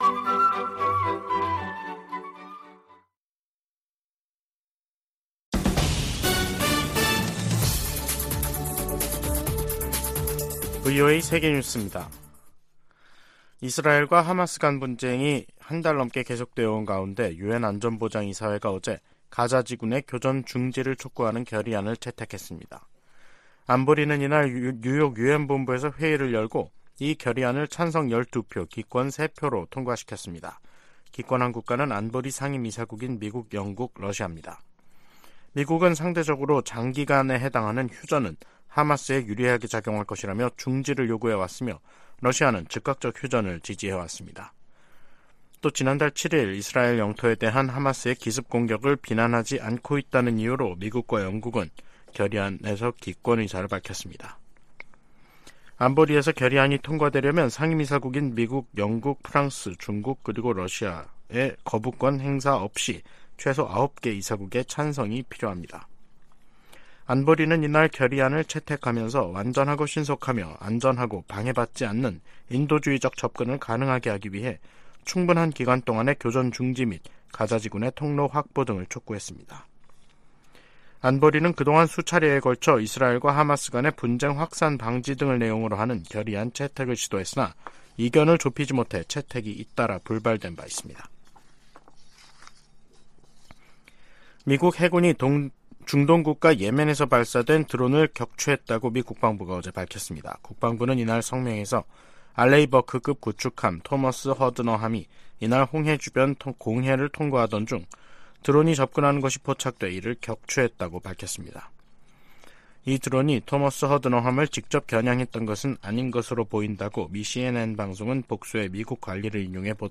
VOA 한국어 간판 뉴스 프로그램 '뉴스 투데이', 2023년 11월 16일 2부 방송입니다. 조 바이든 미국 대통령이 15일 시진핑 중국 국가 주석과의 회담에서 한반도의 완전한 비핵화에 대한 미국의 의지를 다시 한번 강조했습니다. 유엔총회 제3위원회가 북한의 인권 유린을 규탄하는 북한인권결의안을 19년 연속 채택했습니다. 북한과 러시아는 정상회담 후속 조치로 경제공동위원회를 열고 무역과 과학기술 등 협력 방안에 합의했습니다.